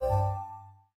pause-back-click.wav